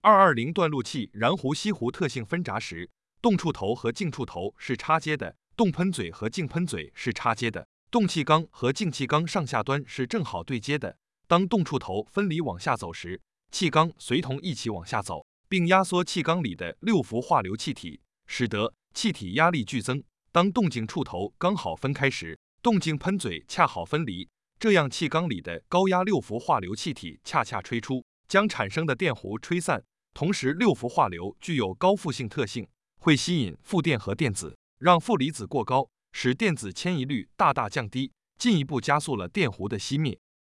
220断路器燃弧熄弧.mp3